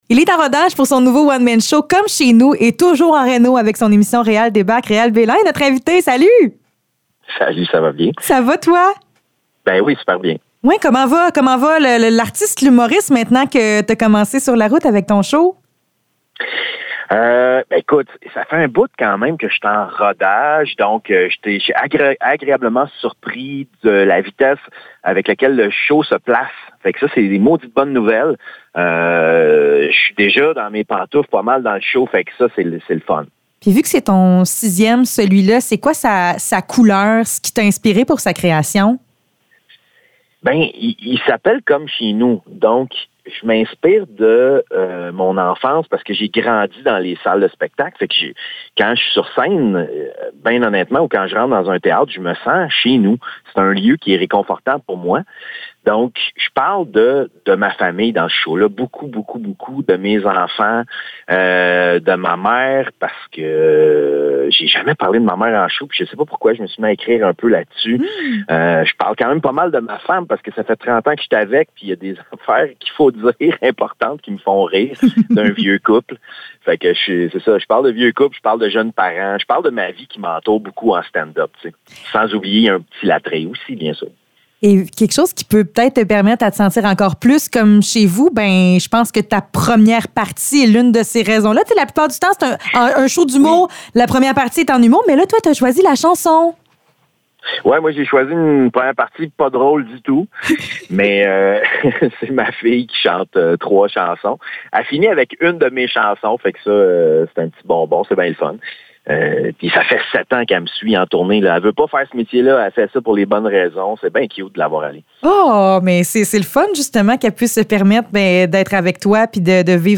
Entrevue avec Réal Béland